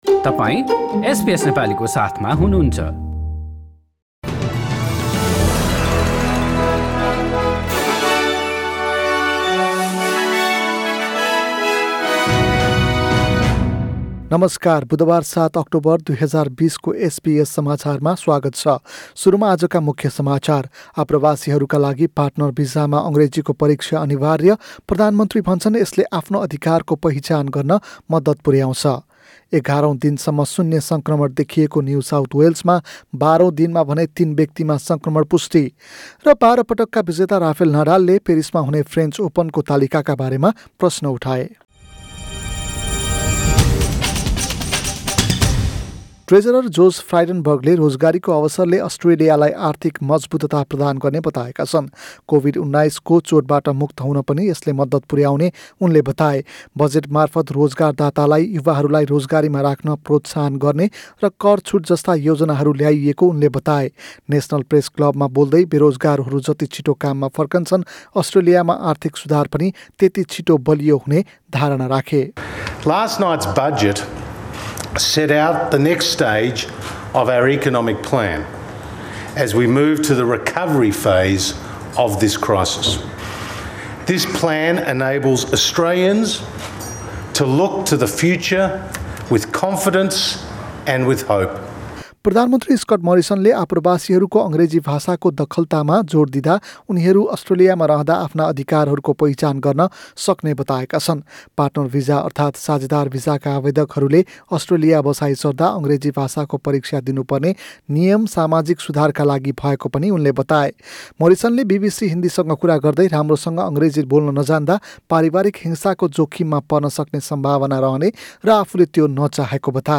Listen to the latest news headlines in Australia from SBS Nepali radio. In this bulletin: Partner visa places boosted in the budget, but more refugees to miss out,New South Wales breaks its streak of zero locally-acquired COVID-19 cases.